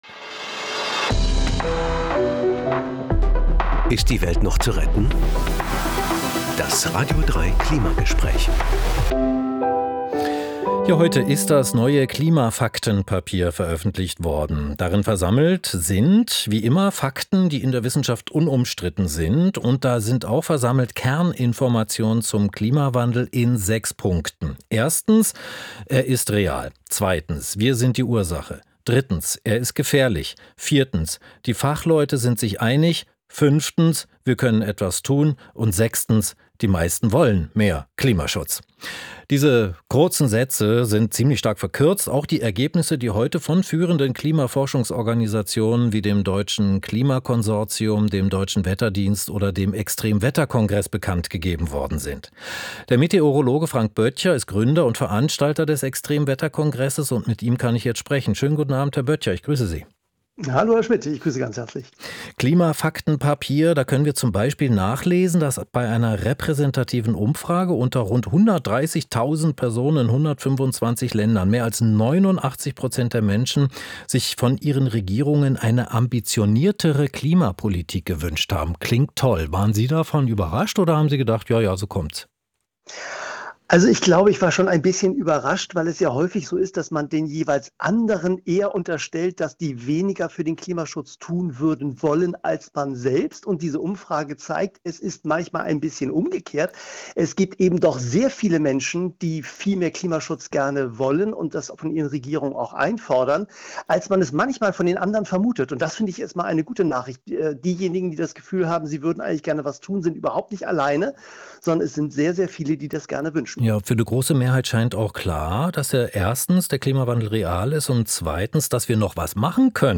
radio3 Klimagespräch: Das neue Klimafaktenpapier wurde heute veröffentlicht